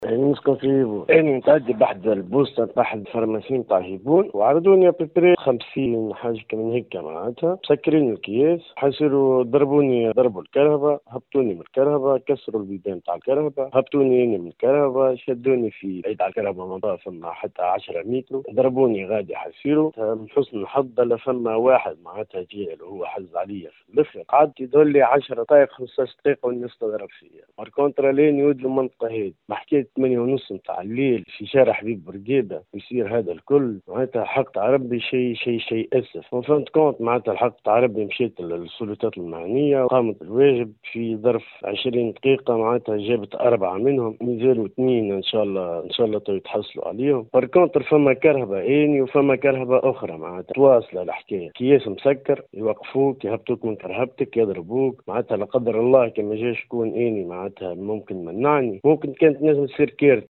استنكر مواطن أصيل المهدية في تصريح ل” ام اف ام” صباح اليوم 13 جانفي 2024 استفحال ظاهرة ” البراكاجات” في المهدية . وأوضح المتصل أن عددا من الأشخاص تعمدوا تهشيم بلور سيارته ثم الإعتداء عليه بالضرب لمدة 10 دقائق.